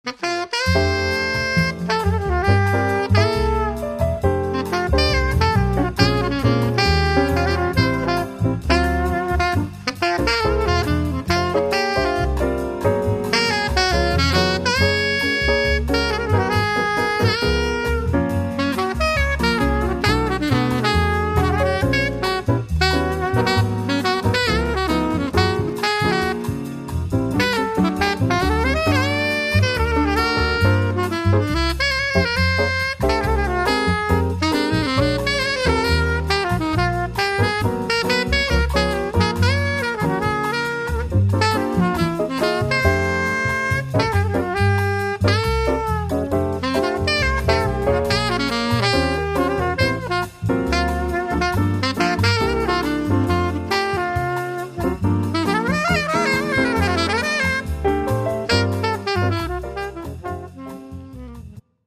Saxophon
Sax Beispiel 2